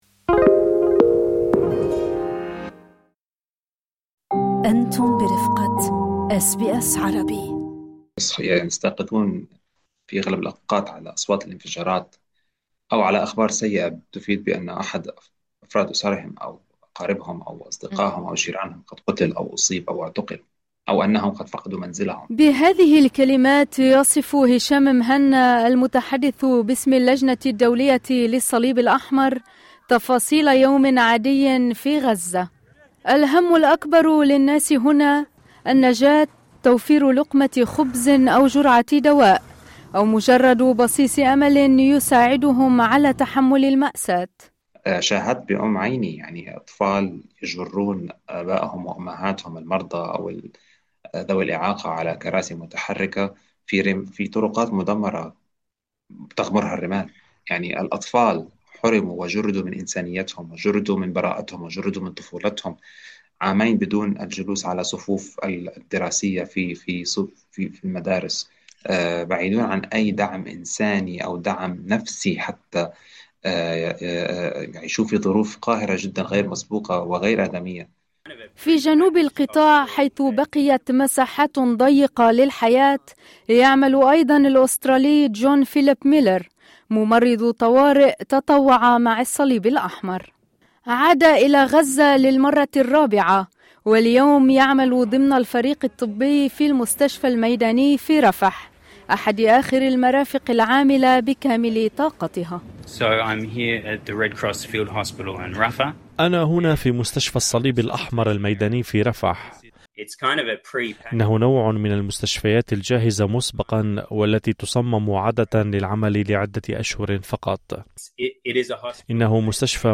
تتواصل الأزمة الإنسانية في غزة مع تزايد معاناة المدنيين تحت القصف والنزوح ونقص الإمدادات. شهادات من العاملين في الصليب الأحمر تكشف عن تحديات طبية ومعيشية غير مسبوقة داخل القطاع.